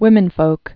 (wĭmĭn-fōk) also wom·en·folks (-fōks)